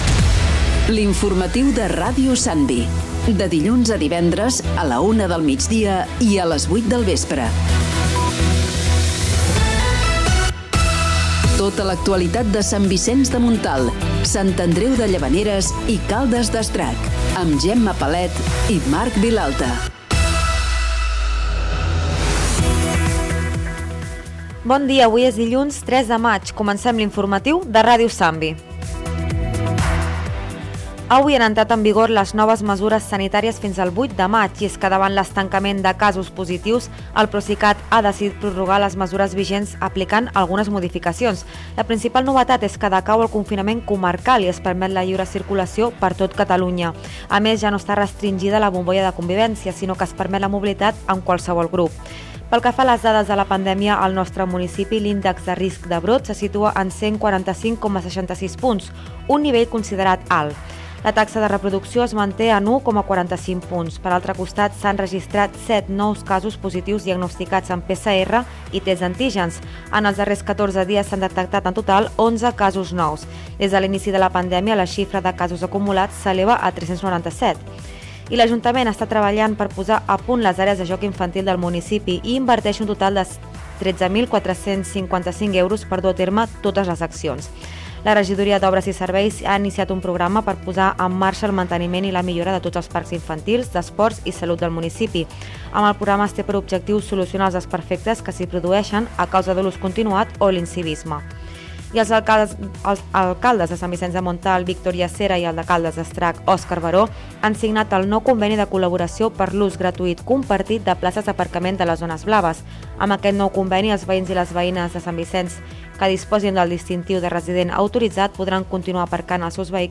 Careta de l'informatiu i sumari: noves mesures del Procicat, àrees de joc infantil, zones blaves de Sant Vicenç i Caldes, etc.
Informatiu